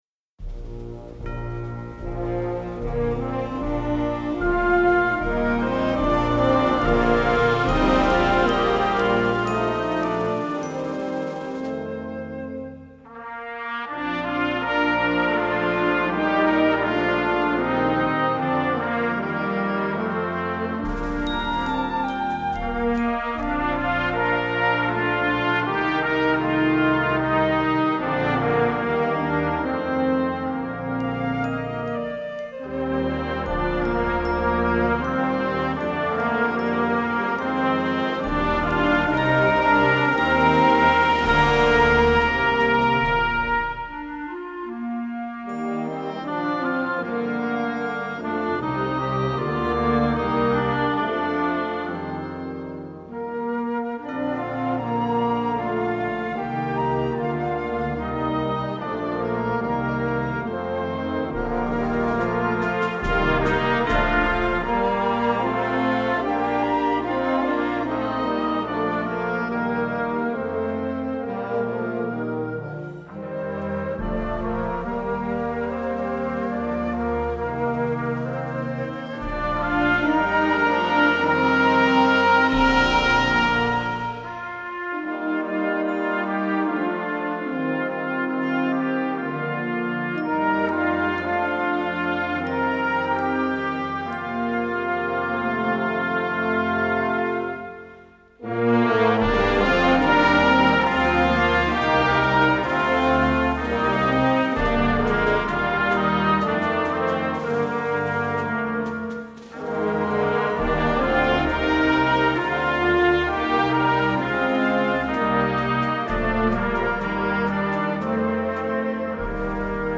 inspirational, easter, sacred, instructional, children